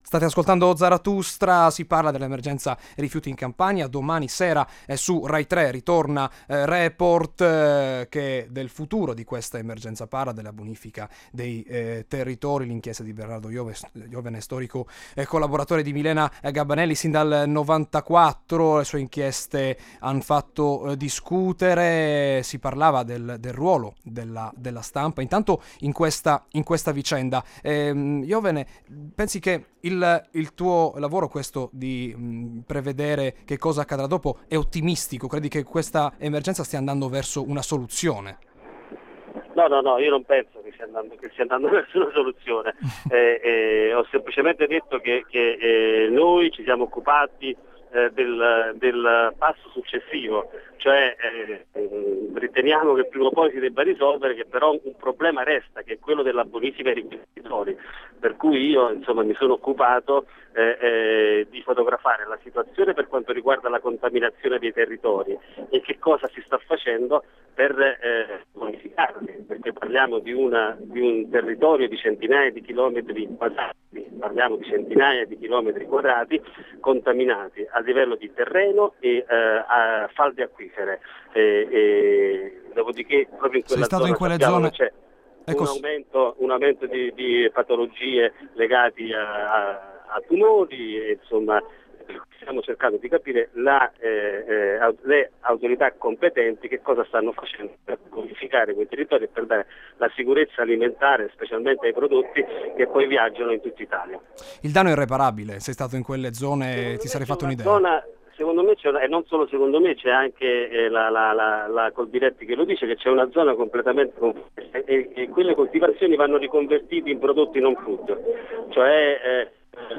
Ascolta la puntata di Zarathustra andata in onda sabato 8 marzo, alle 18,05, su Radio Italia anni '60 - Emilia Romagna.